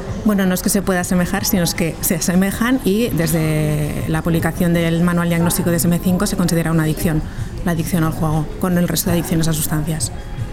Una jornada de HISPACOOP sobre juego responsable en la sede de la ONCE repasa los retos que afronta este compromiso y acoge la presentación del último proyecto de investigación sobre esta materia galardonado por la Organización